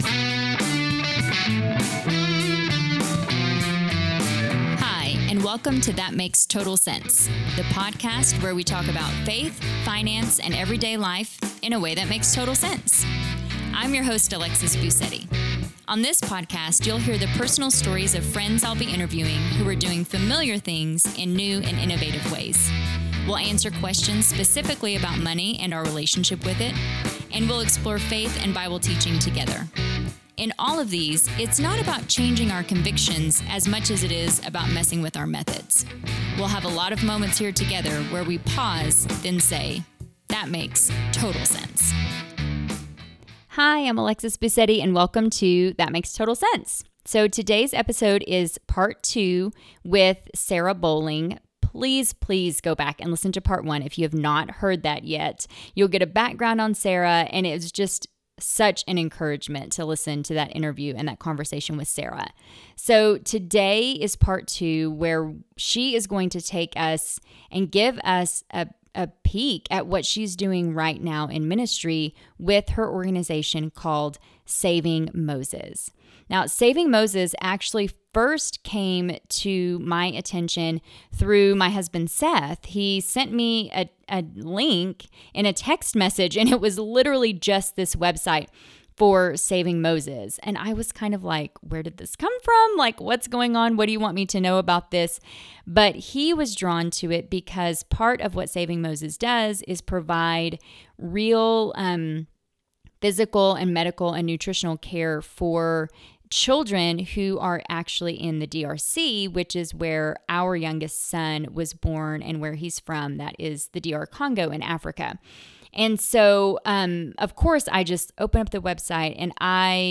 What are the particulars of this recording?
While no explicit language was used in the interview, you will definitely want to listen away from younger ears.